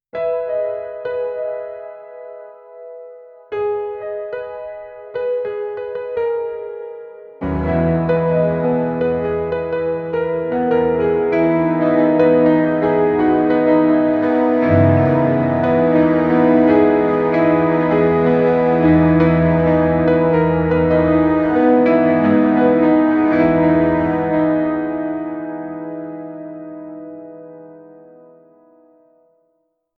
Create a 30s original ringtone for a serious businessman